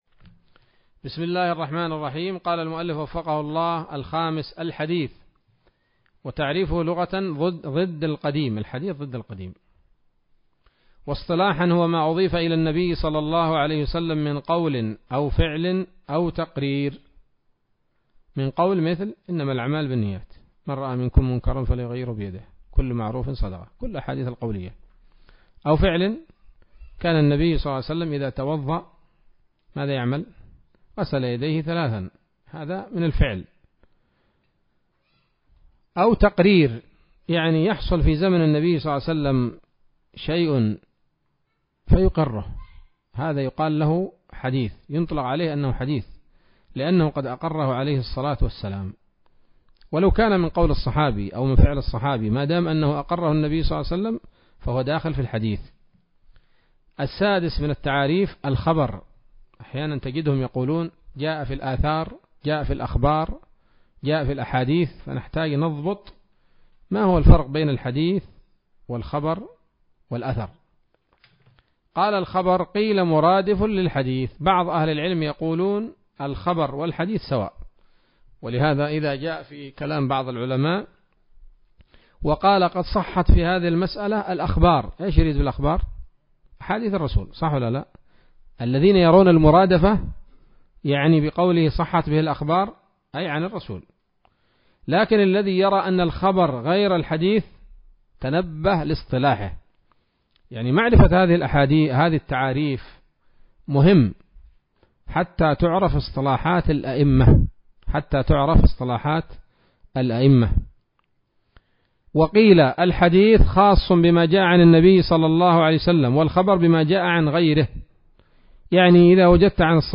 الدرس الثالث من الفتوحات القيومية في شرح البيقونية [1444هـ]